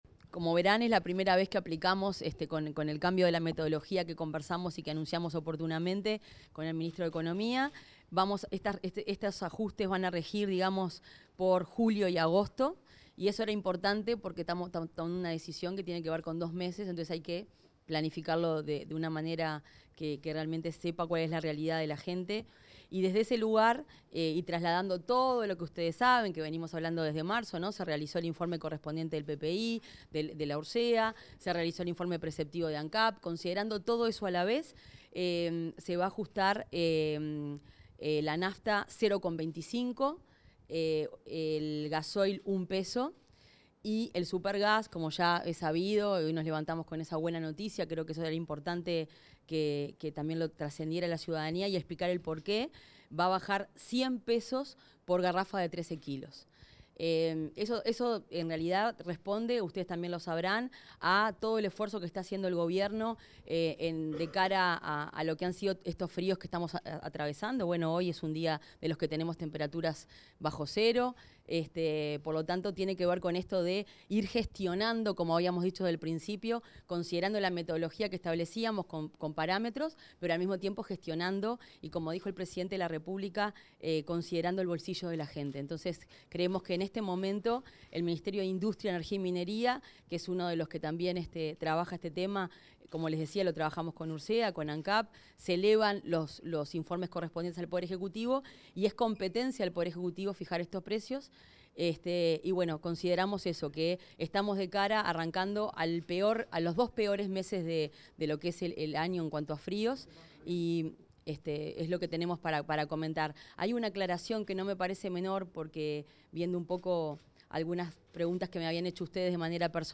Declaraciones de la ministra de Industria, Fernanda Cardona
Declaraciones de la ministra de Industria, Fernanda Cardona 30/06/2025 Compartir Facebook X Copiar enlace WhatsApp LinkedIn La ministra de Industria, Energía y Minería, Fernanda Cardona, realizó declaraciones a la prensa en la Torre Ejecutiva, en referencia a los nuevos precios de los combustibles fijados por el Gobierno.